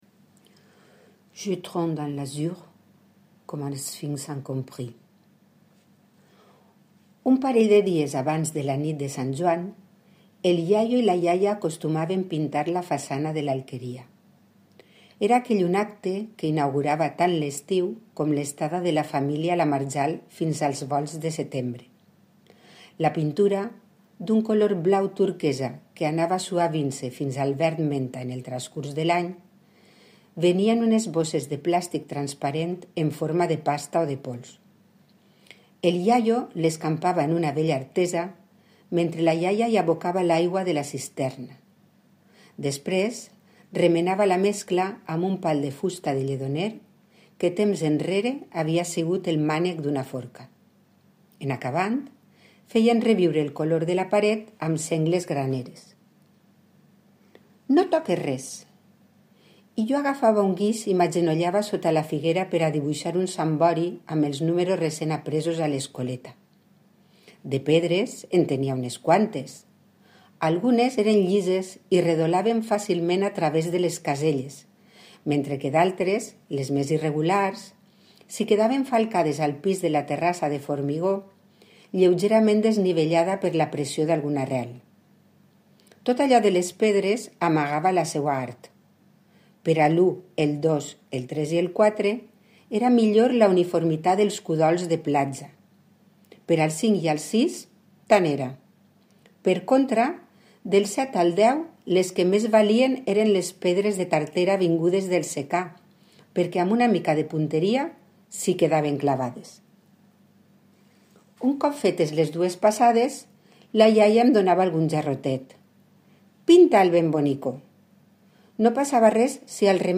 recitació